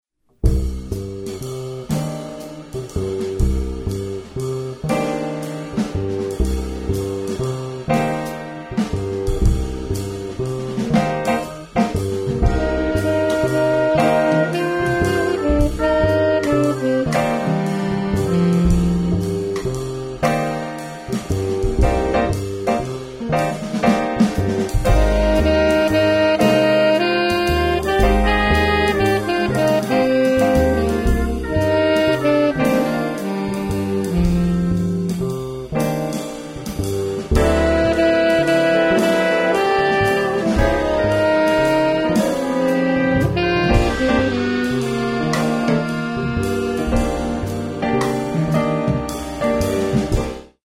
Hot and Cool Jazz, Swing and Bebop Music
Piano
Trumpet
Tenor Sax
Bass
Drums